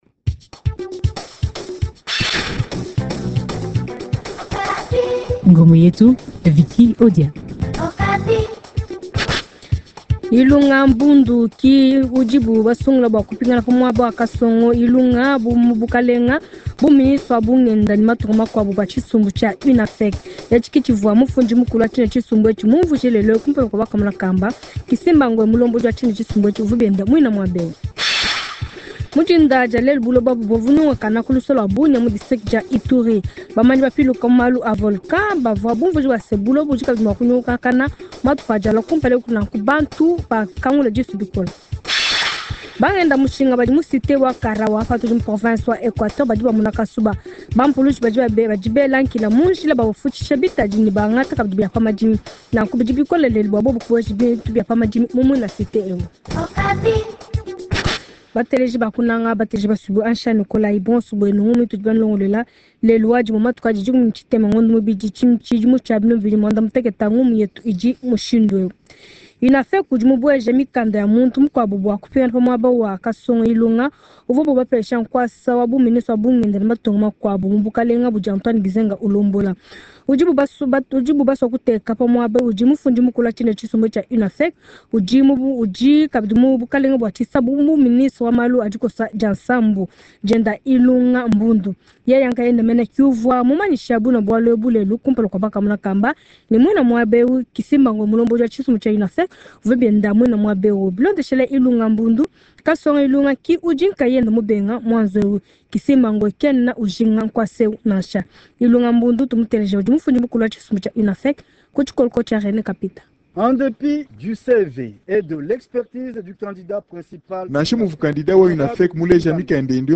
Journal Tshiluba